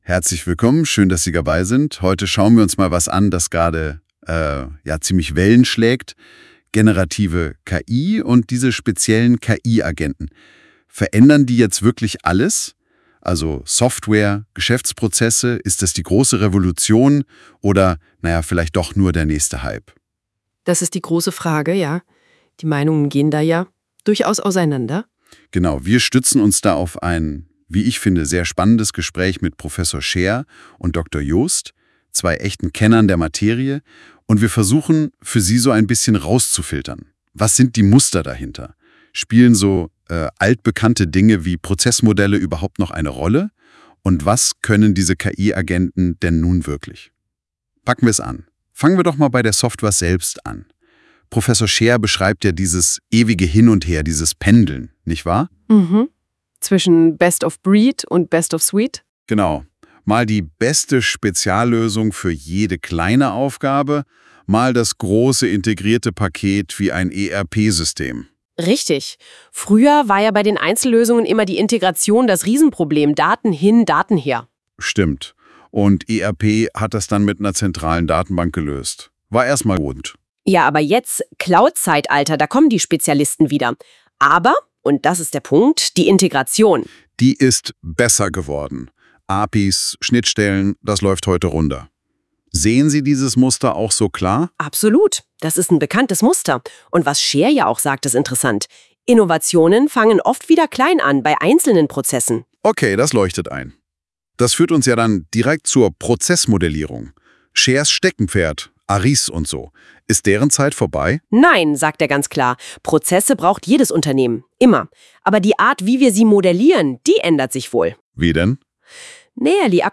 Das Gespräch als automatisch erstellter Podcast